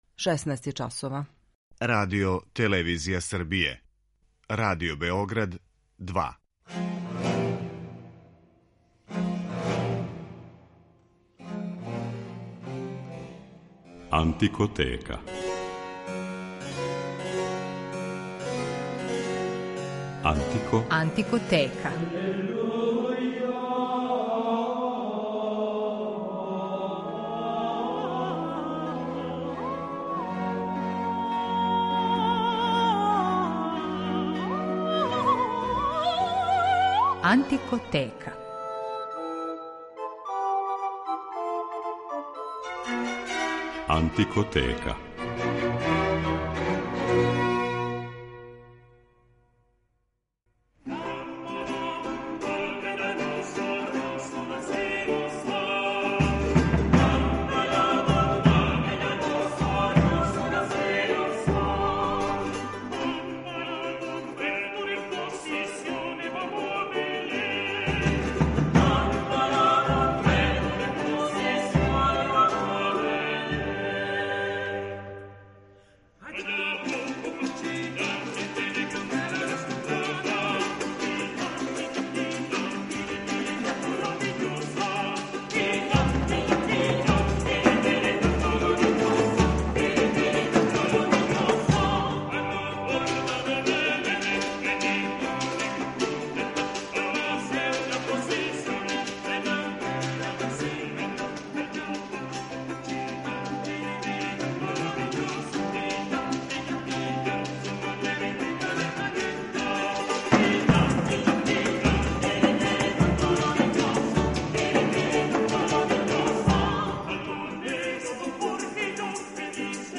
Данашња емисија посвећена је латино-америчкој музици 17. века коју ћете слушати у извођењу кубанског ансамбал "Арс Лонга". У рубрици Антикоскоп говорићемо о једном старом, скоро заборављеном инструменту, triple pipe , својеврсној трострукој свирали која се по нацину свирања категоризује као усне оргуље, а по грађи писка као троструки кларинет.